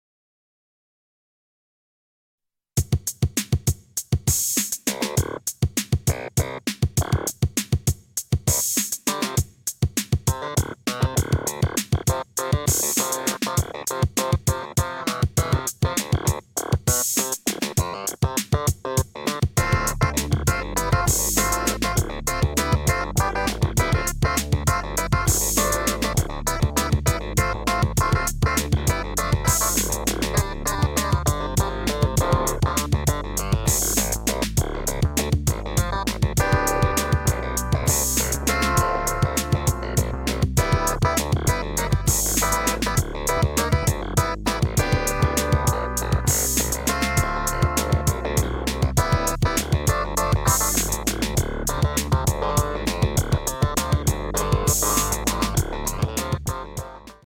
ln-My PC3 Clavinet - 7_4 funky improvisation.mp3